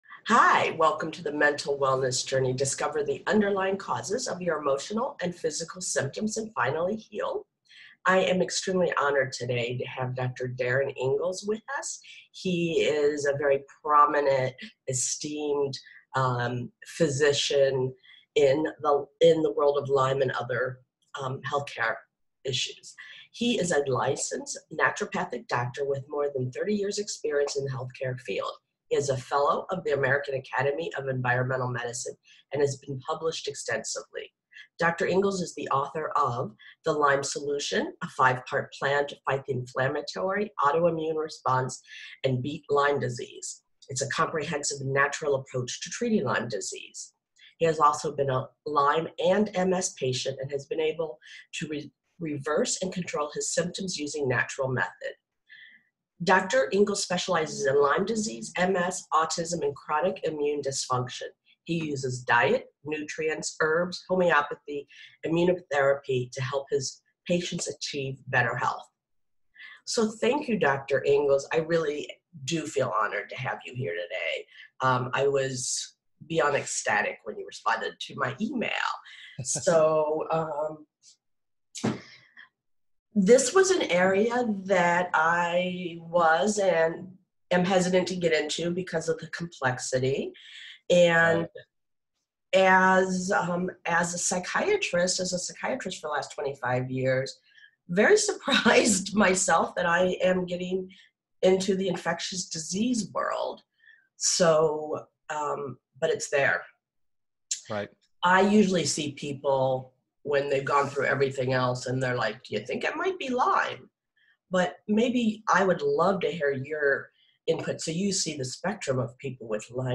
Lyme & Mental Health Discussion